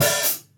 Live_Open_Hat.wav